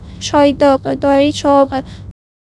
persian-tts-female-GPTInformal-Persian-vits like 0